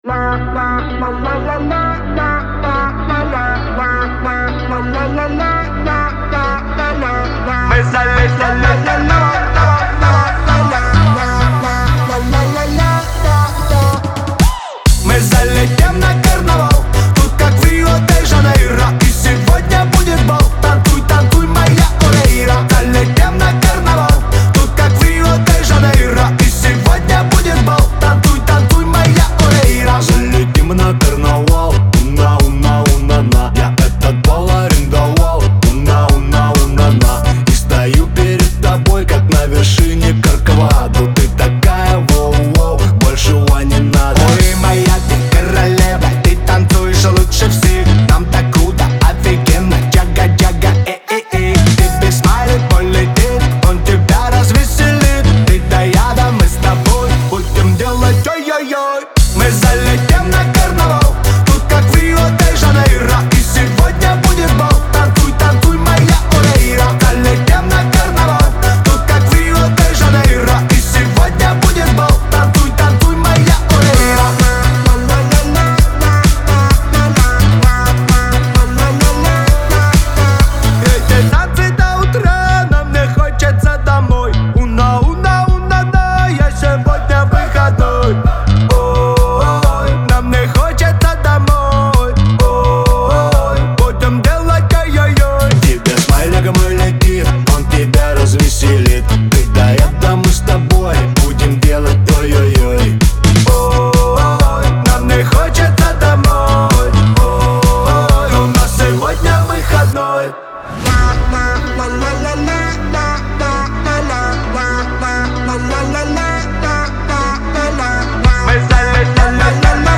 Жанр: ruspop